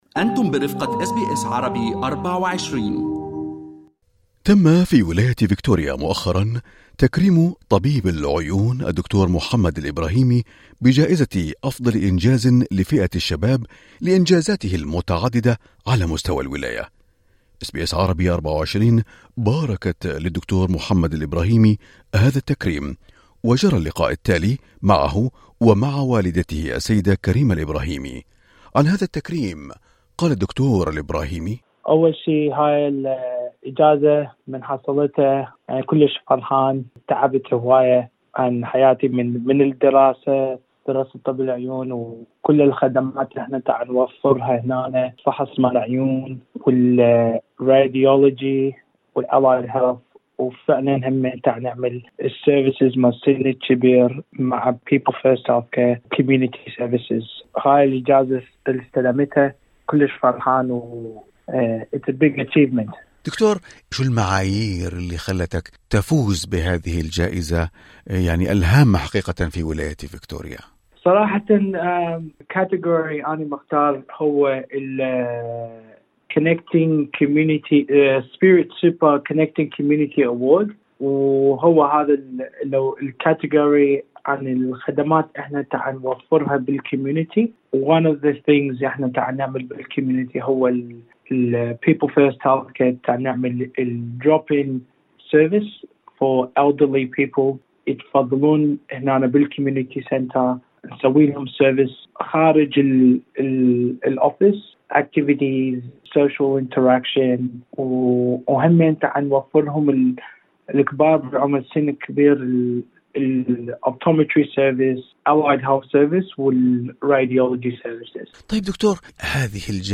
في لقائه مع أس بي أس عربي 24